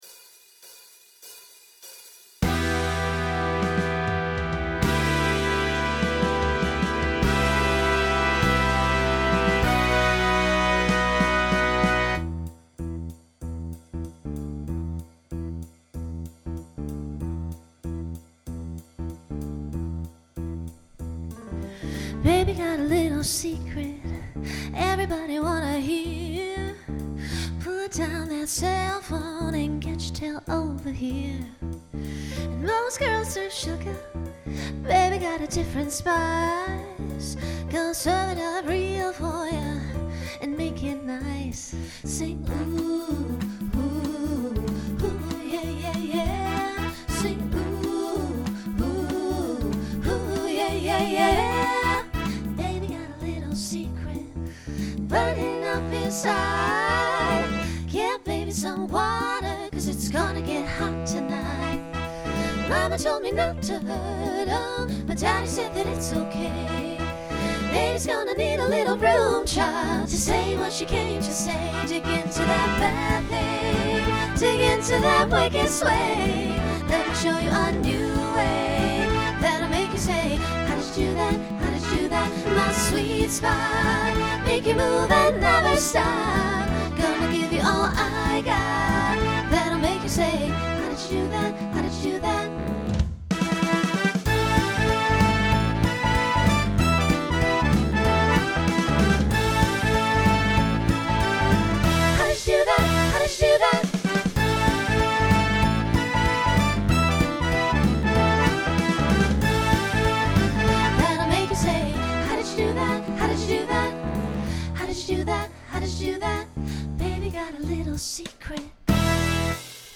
Genre Disco , Pop/Dance , Swing/Jazz
Voicing Mixed